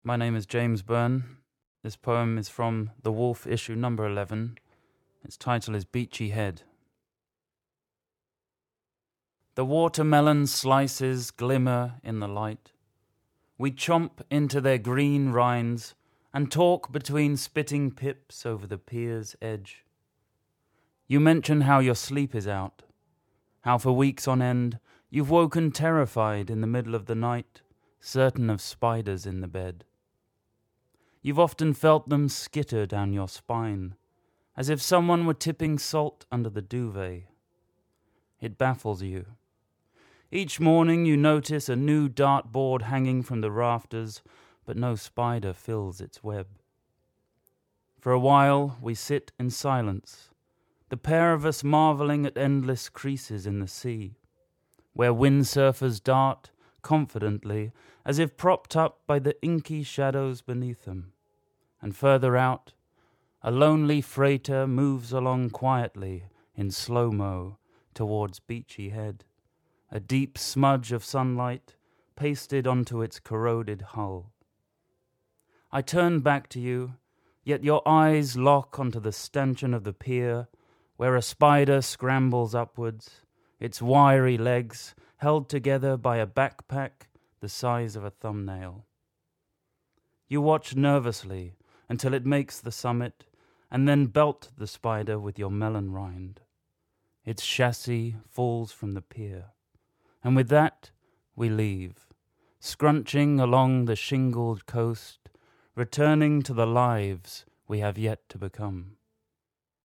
poetrymagazines' note: recorded at Southbank Centre on 9th December 2006.